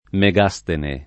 [ me g#S tene ]